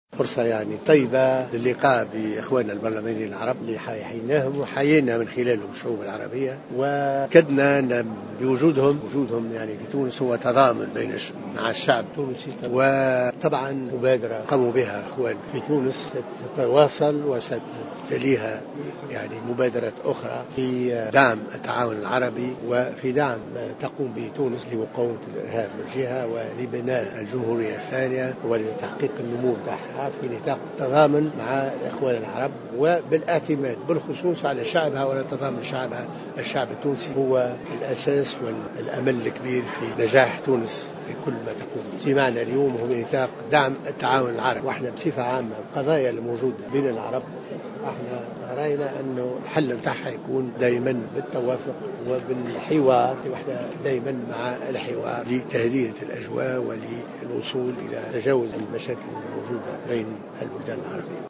أكد رئيس مجلس نواب الشعب محمد الناصر في تصريح اعلامي اليوم الخميس على هامش الجلسة الافتتاحية للبرلمان العربي أن هذه الجلسة ستكون فرصة طيبة للقاء بالبرلمانين العرب وفق قوله.